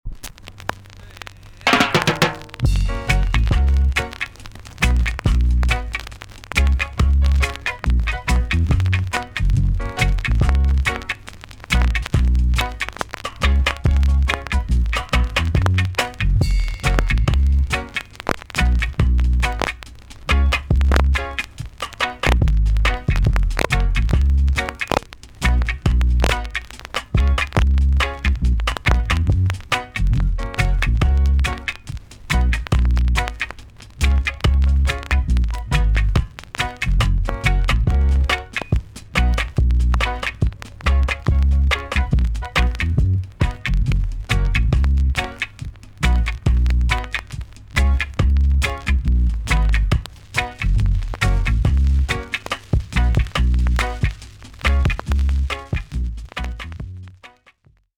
B.SIDE Version
VG ok 軽いチリノイズ、プチノイズが少し入ります。